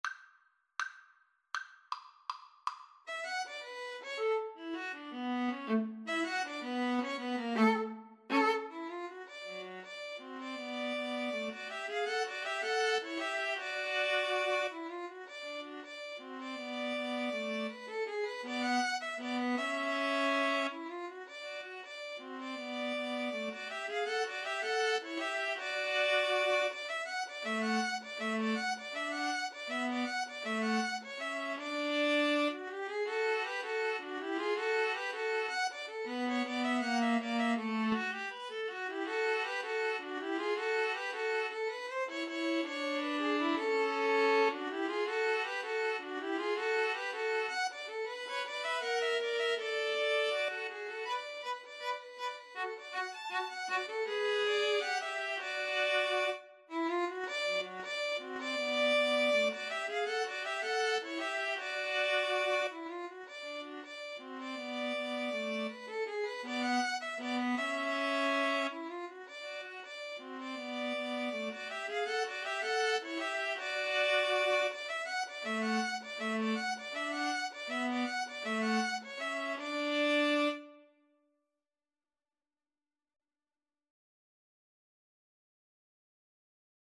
Play (or use space bar on your keyboard) Pause Music Playalong - Player 1 Accompaniment Playalong - Player 3 Accompaniment reset tempo print settings full screen
D major (Sounding Pitch) (View more D major Music for String trio )
=250 Presto (View more music marked Presto)
Jazz (View more Jazz String trio Music)